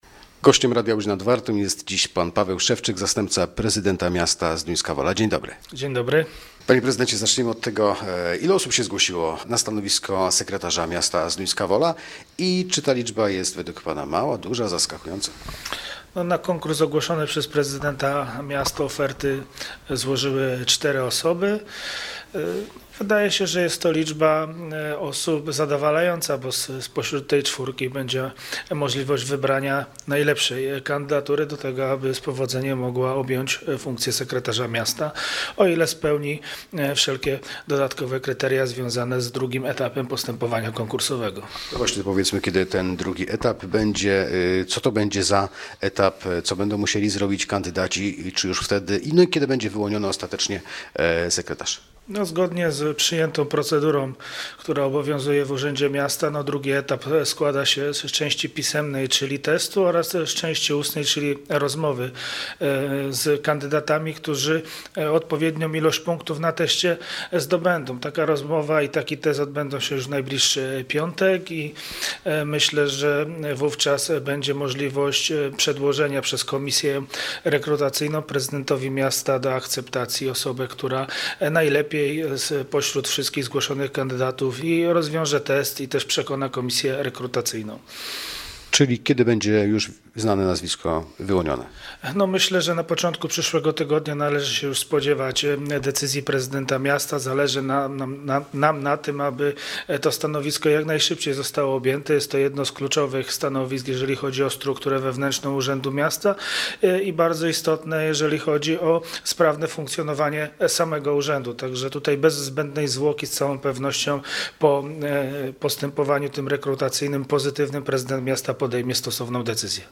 Gościem Radia Łódź Nad Wartą był wiceprezydent Zduńskiej Woli, Paweł Szewczyk.